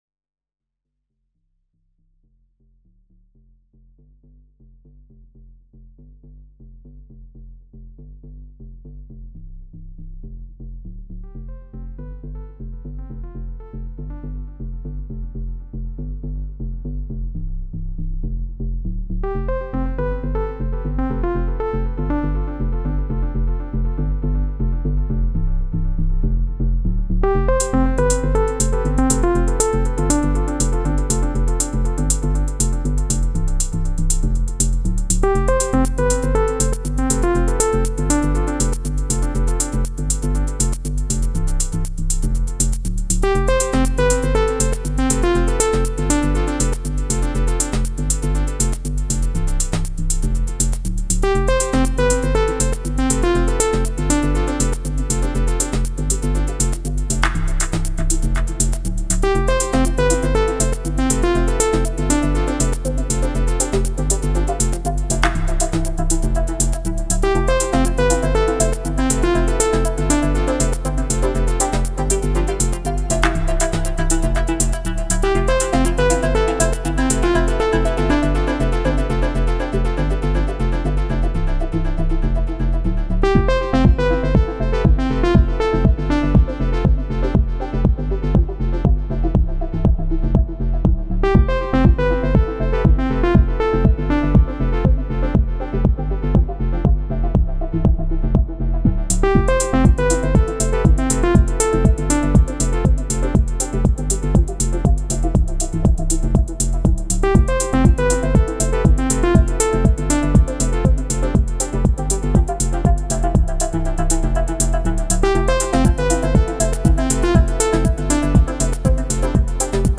a model samples and a td;3 can do so much, sad you cant record the m;s tracks separate, anyways, here is a raw techno just jammed few minutes ago.